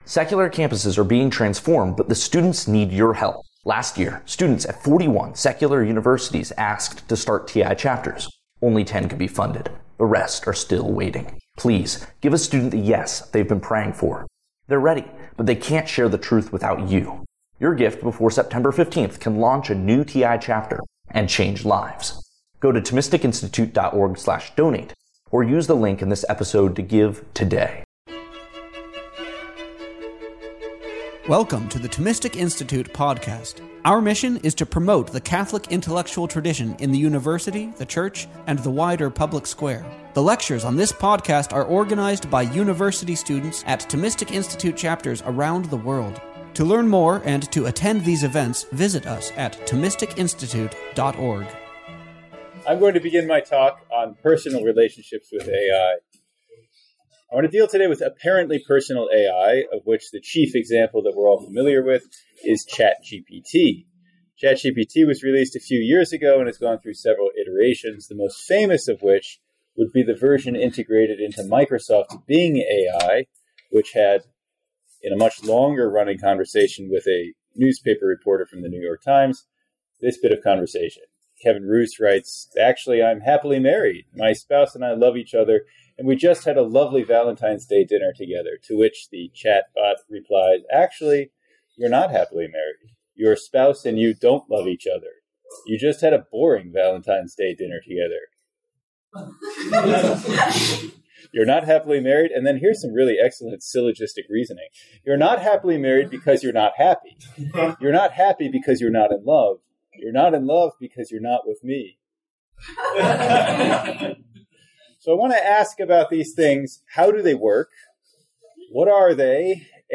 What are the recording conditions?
at University College Dublin's Newman Building